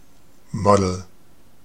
Ääntäminen
IPA : /ˈmɒdl̩/
IPA : /ˈmɑdl̩/